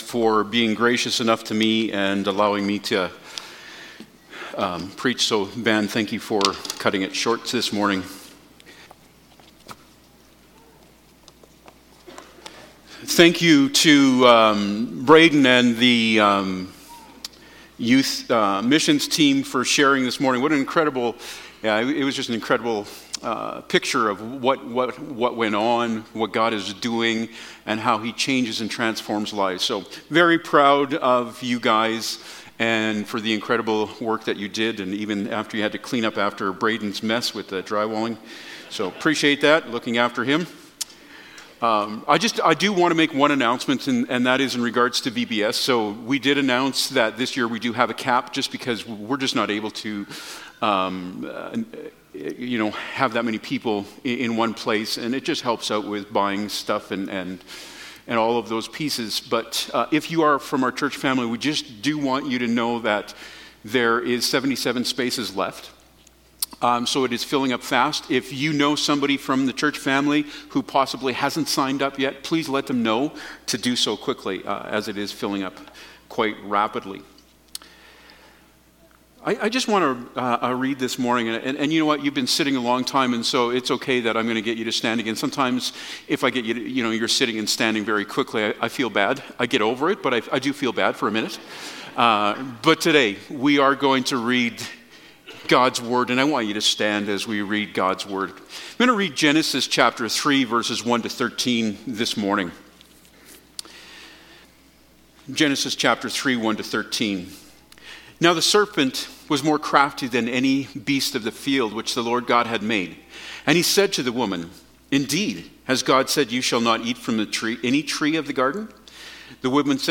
On Guard Passage: Genesis 3:6-13 Service Type: Sunday Morning « Creation